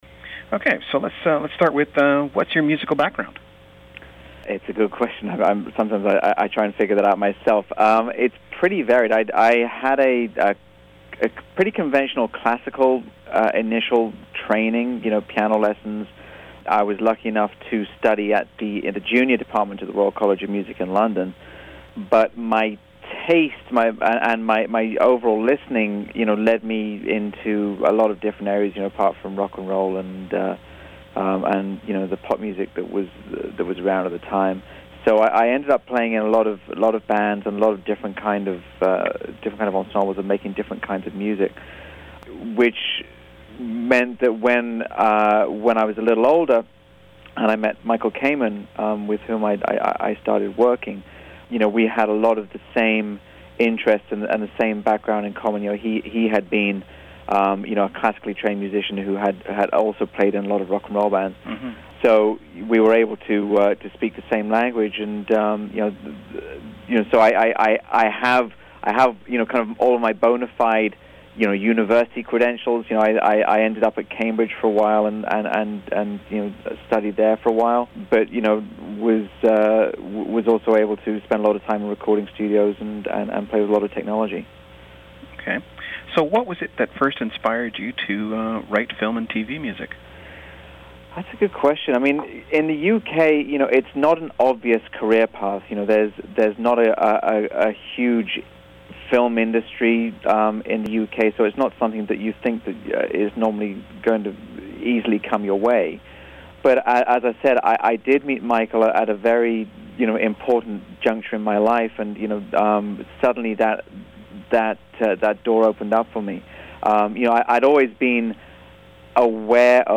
Interview with Edward Shearmur from 2007